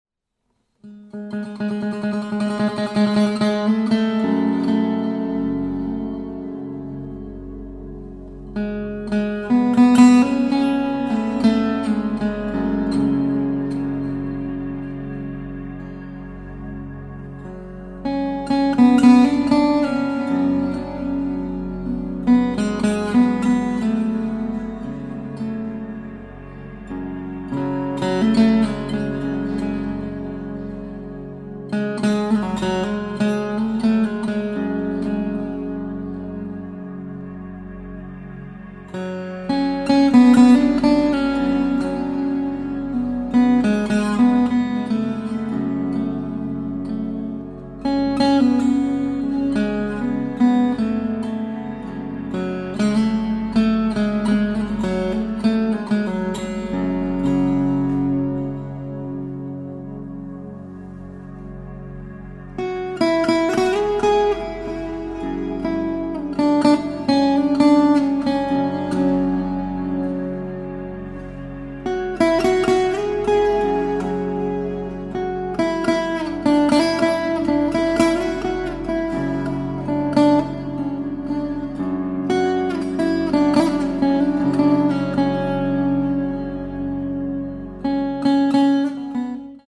Contemporary
, Relaxing / Meditative